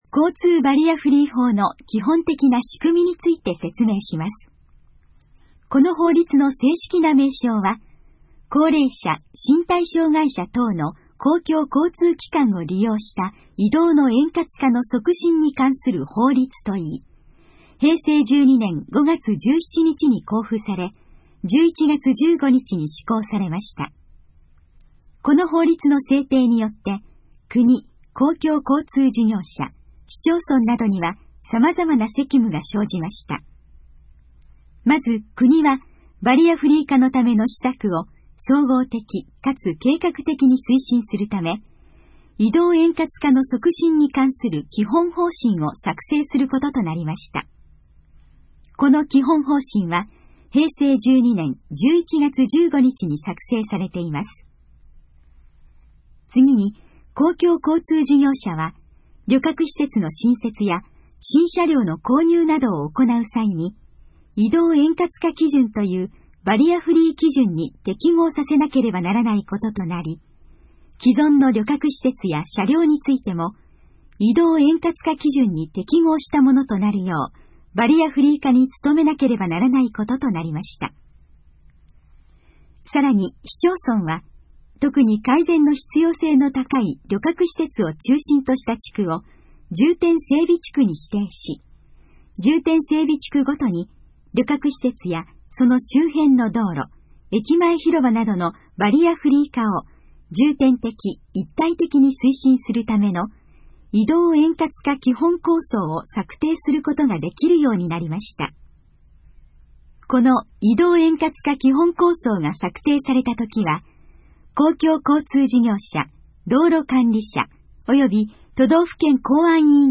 このページの要約を音声で読み上げます。
ナレーション再生 約280KB